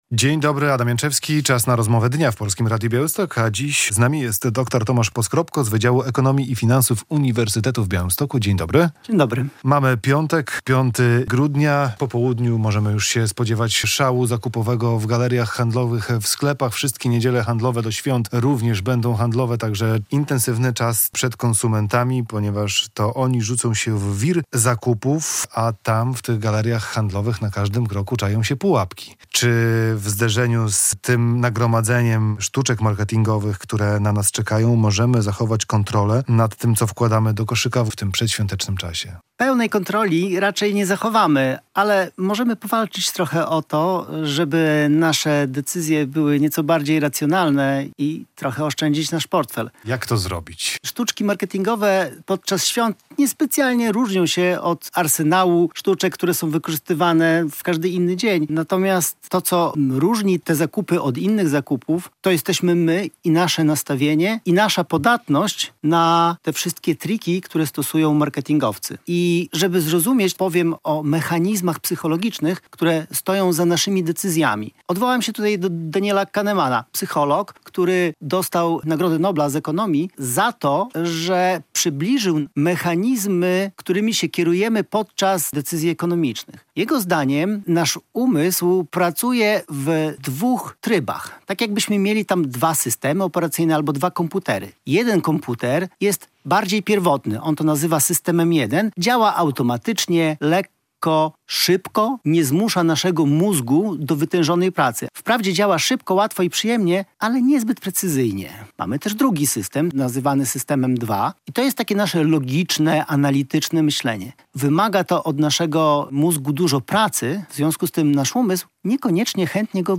Polityka, gospodarka, zdrowie, nauka, sport - codzienna rozmowa z gościem Polskiego Radia Białystok o wszystkich najważniejszych sprawach dotyczących nie tylko regionu.